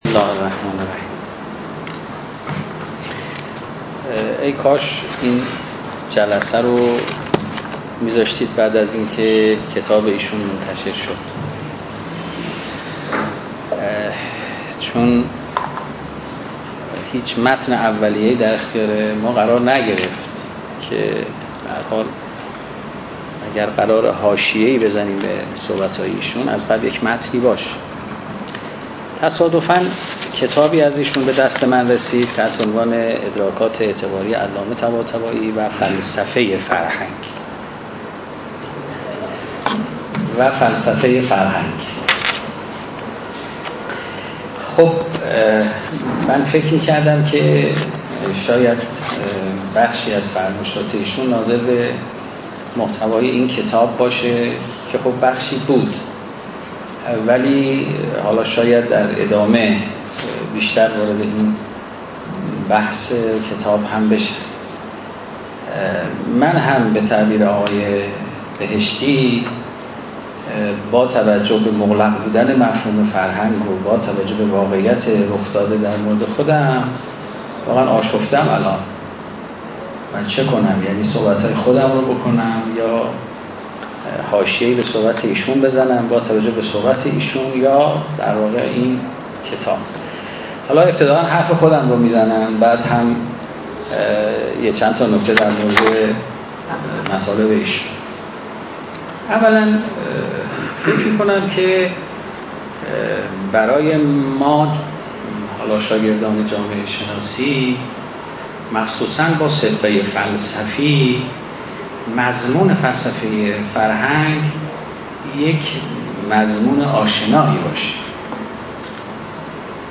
فرهنگ امروز : فایل حاضر سخنرانی عماد افروغ در ششمین نشست از نشست‌های خانه اندیشمندان علوم انسانی با وضوع «فلسفه وفرهنگ» در تاریخ سی‌ام اردیبهشت ماه است.